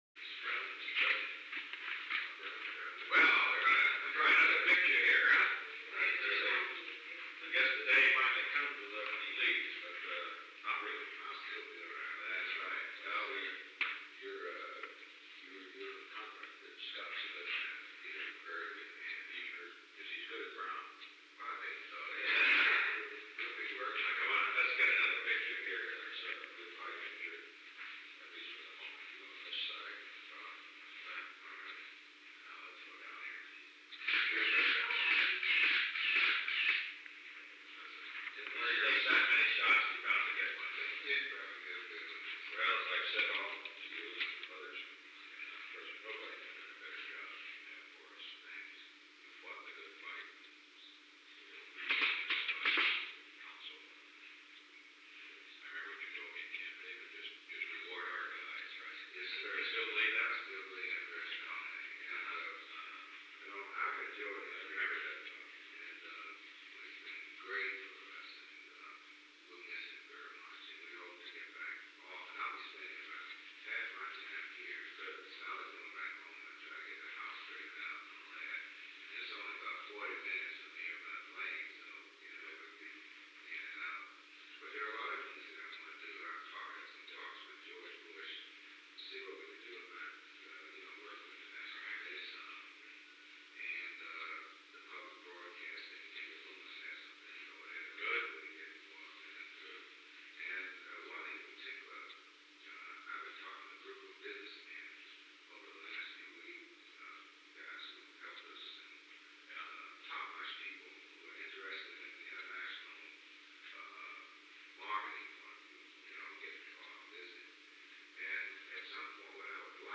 Conversation: 848-013
Recording Device: Oval Office
The Oval Office taping system captured this recording, which is known as Conversation 848-013 of the White House Tapes.